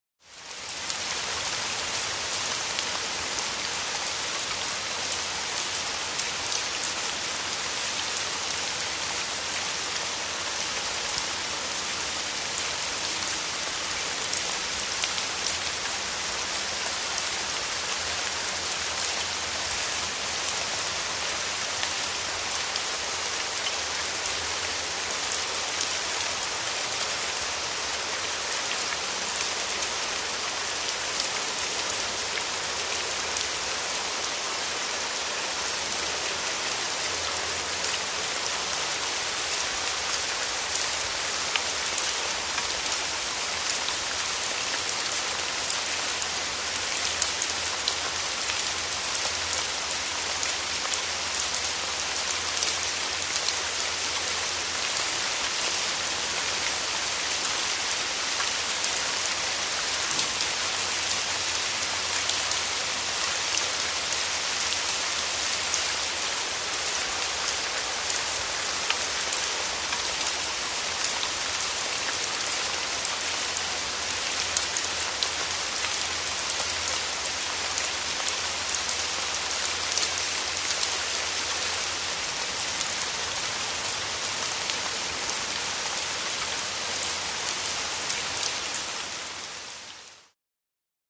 Download Heavy Rain sound effect for free.
Heavy Rain